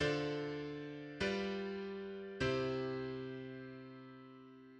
ii–V–I progression (with seventh chords) in C major